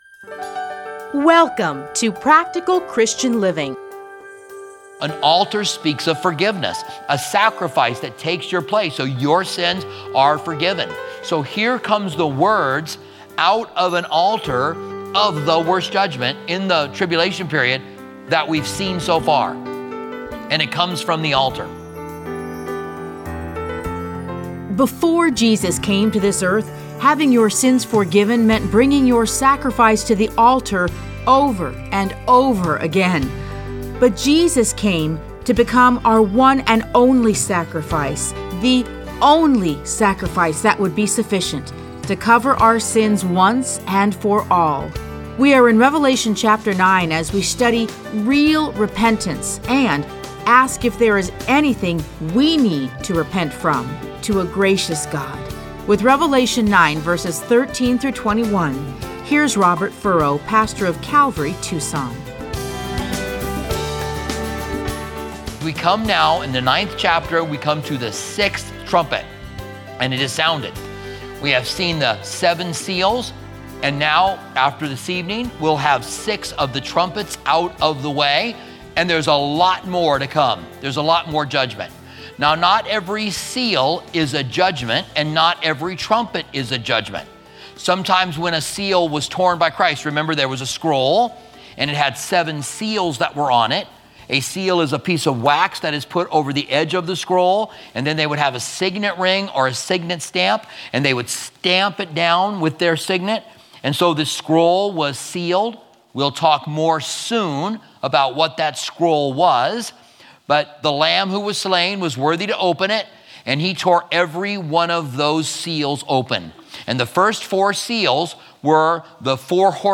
Listen to a teaching from Revelation 9:13-21.